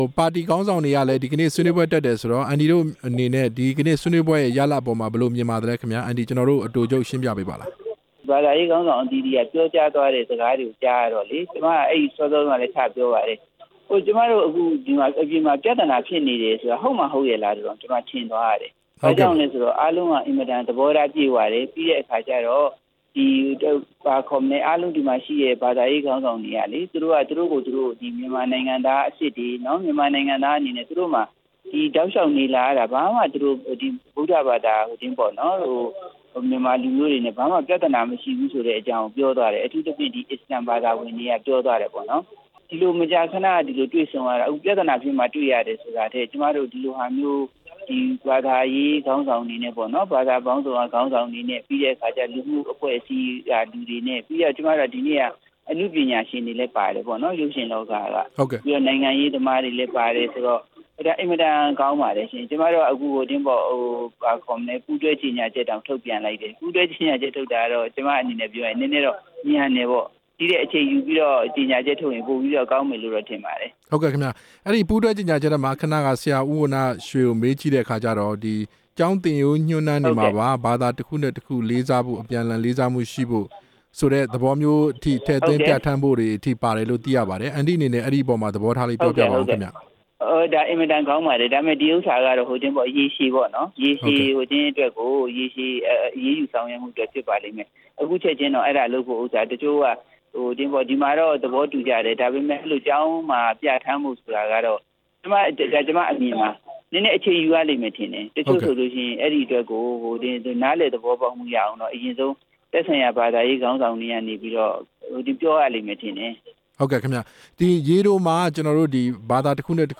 ဒီမိုကရက်တစ်ပါတီ(မြန်မာ)ခေါင်းဆောင် ဒေါ်သန်းသန်းနုနဲ့ မေးမြန်းချက်